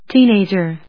/‐èɪdʒɚ(米国英語), ‐èɪdʒə(英国英語)/